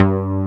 MOOGBAS1.wav